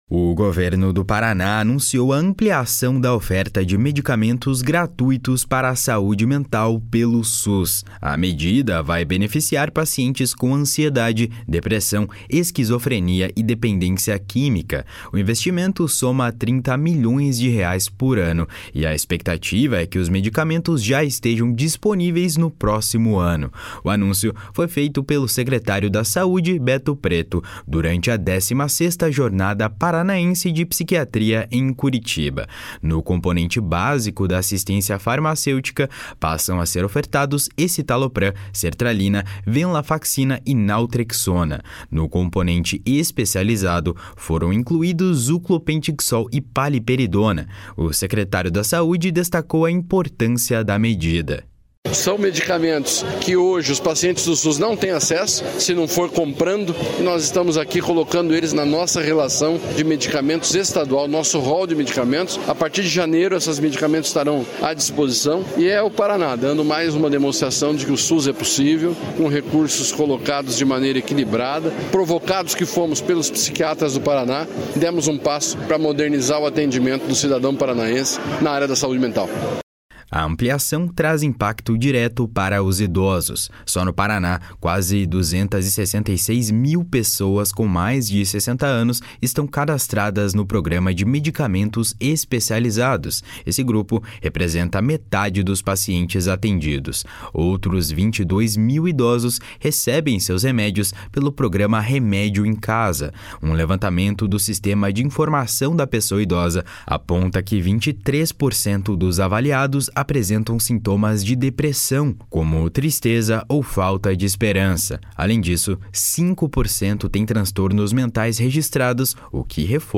O secretário da Saúde, Beto Preto, destacou a importância da medida. // SONORA BETO PRETO //